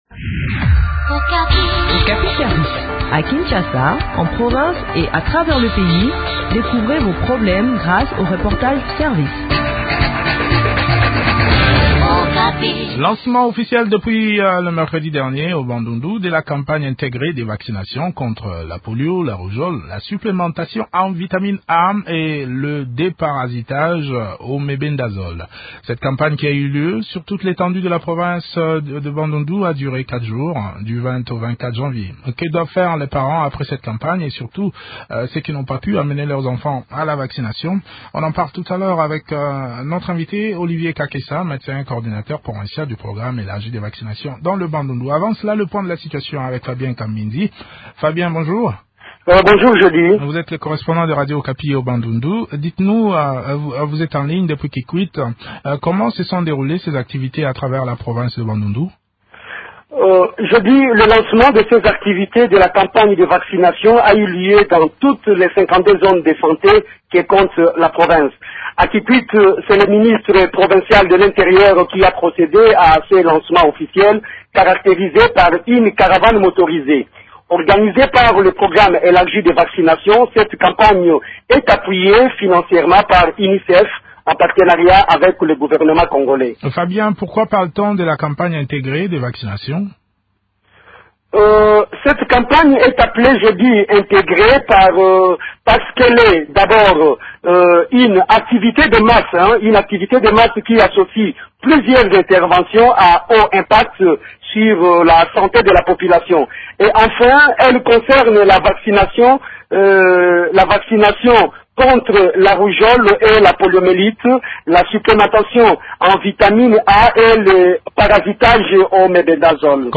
s’entretient sur l’organisation de cette campagne